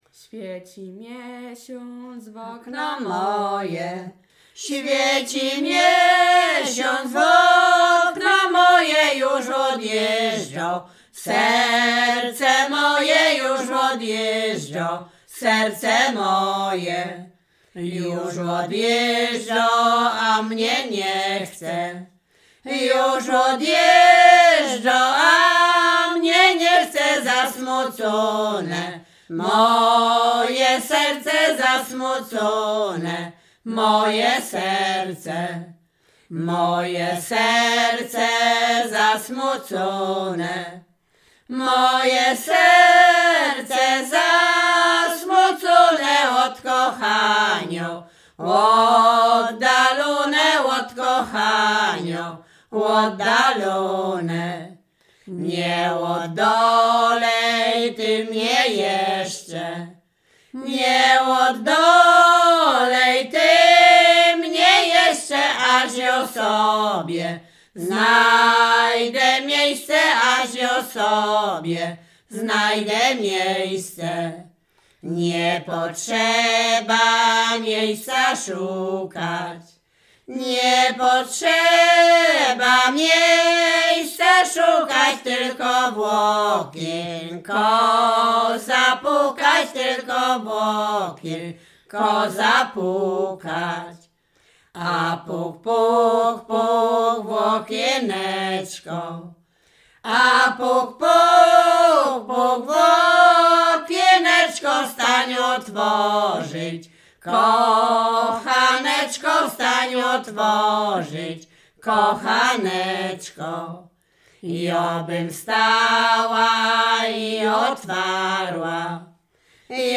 Śpiewaczki z Mroczek Małych
województwo łodzkie, powiat sieradzki, gmina Błaszki, wieś Mroczki Małe
liryczne miłosne